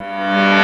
Index of /90_sSampleCDs/USB Soundscan vol.03 - Pure Electro [AKAI] 1CD/Partition E/04-PIANOS